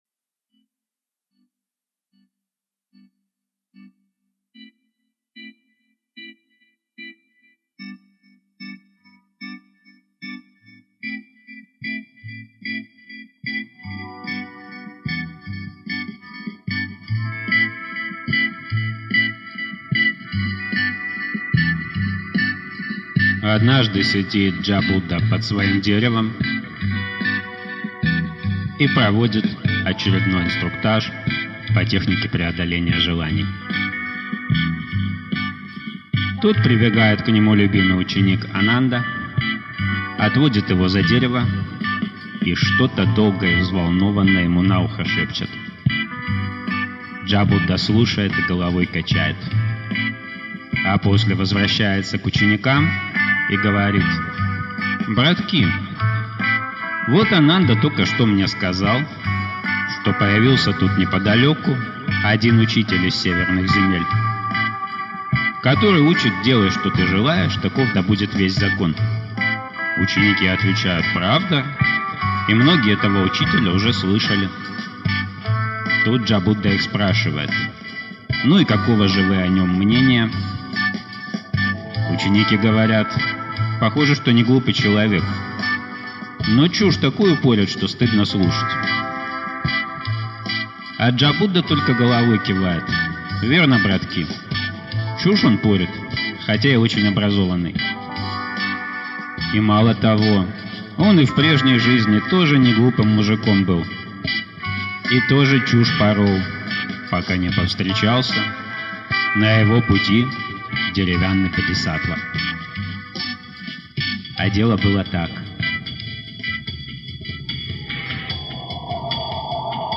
Аудиокнига Джатака о деревянном бодхисаттве | Библиотека аудиокниг